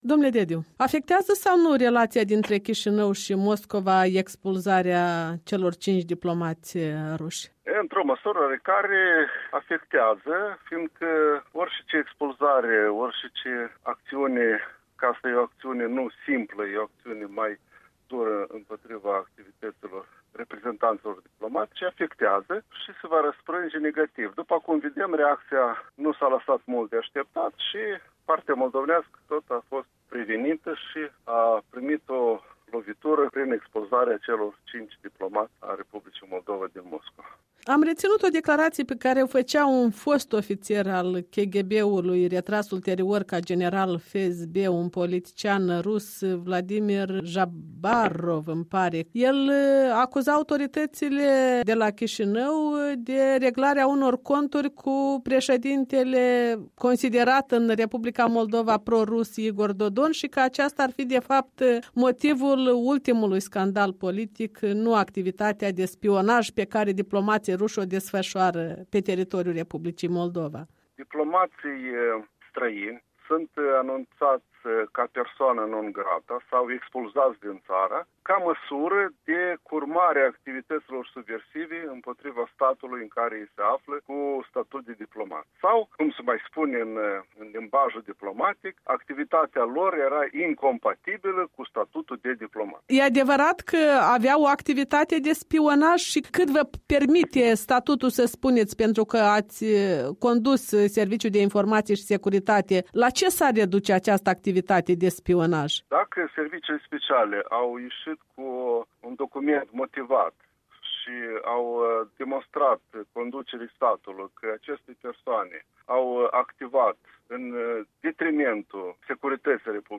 Interviu cu Valentin Dediu, fost director adjunct al SIS